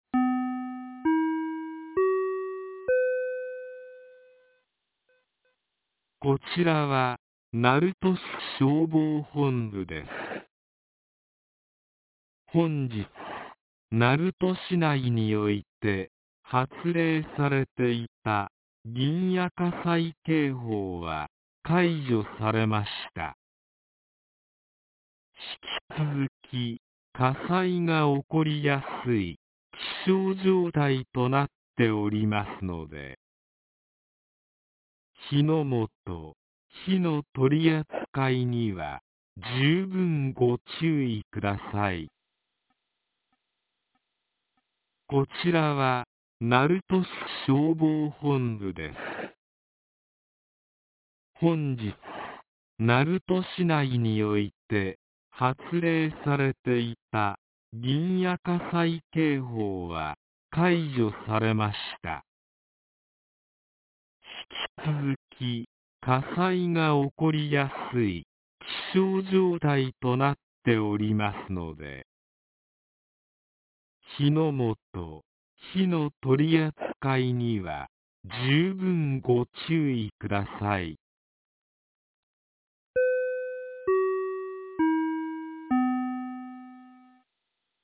2026年01月23日 13時16分に、鳴門市より大麻町-大谷へ放送がありました。